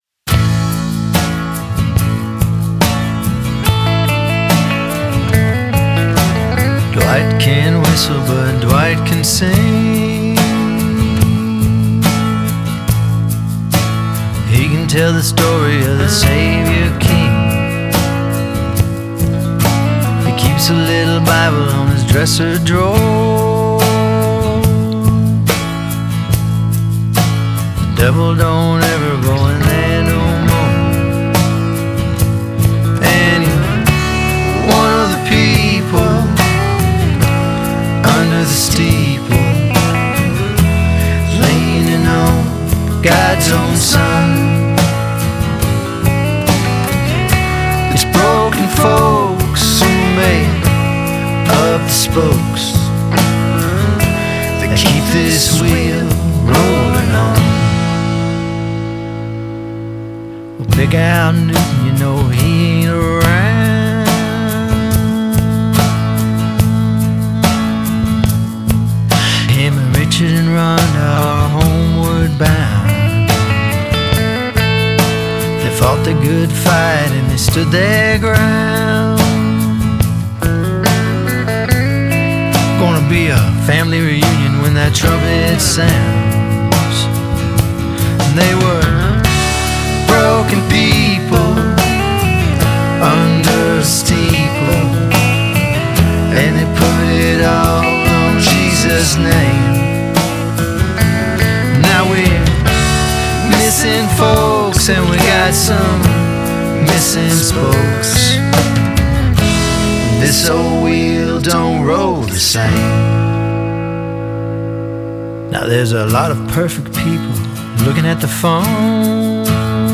Com uma voz marcada pela experiência e pelo calor humano